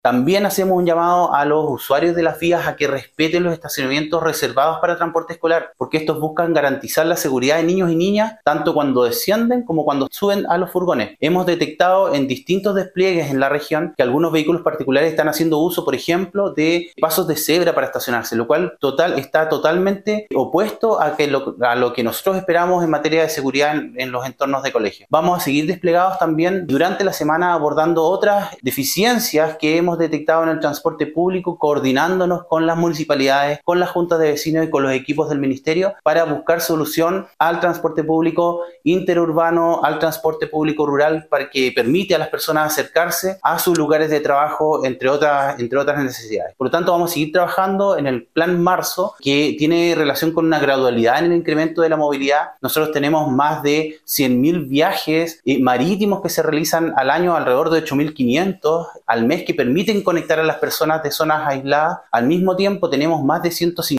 La autoridad además realizó un llamado a respetar los estacionamientos demarcados para los vehículos de transporte escolar: